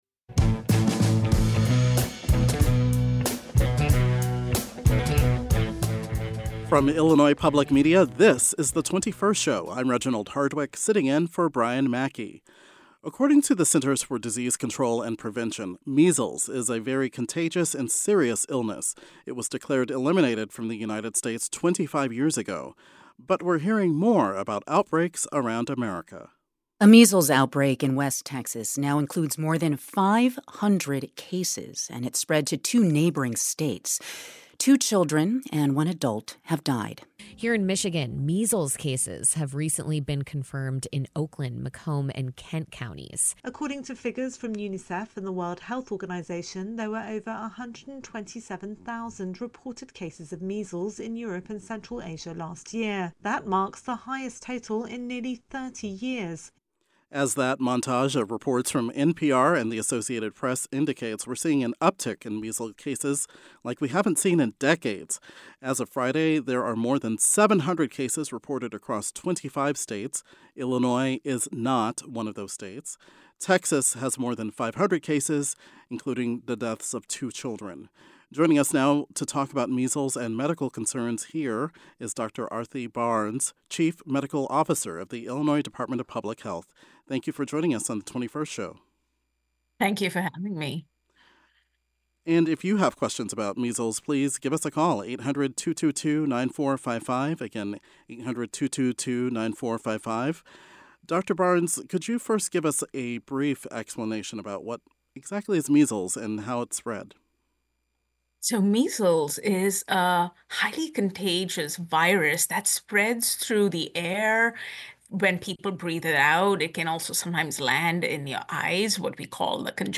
An official from the state department of health joins the program to discuss what symptoms the public should look out for and how Illinois would handle a potential outbreak.